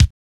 LINN DRUM K.wav